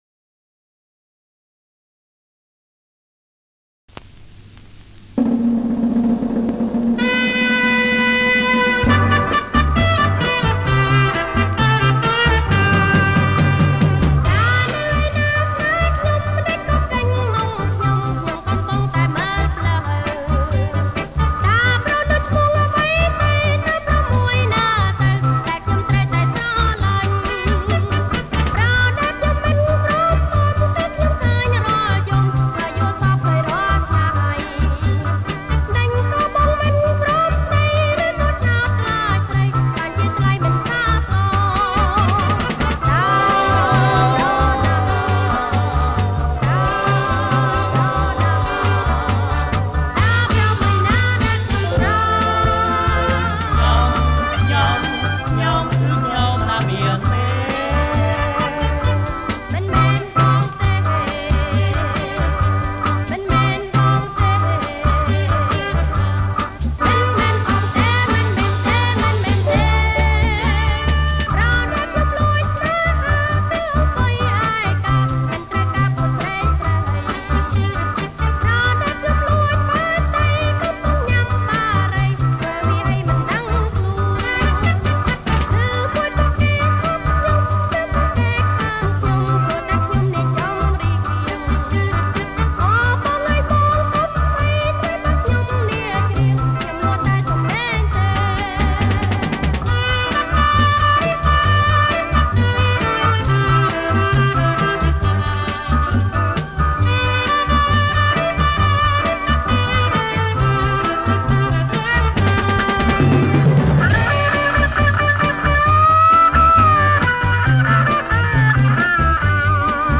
• ប្រគំជាចង្វាក់ Jerk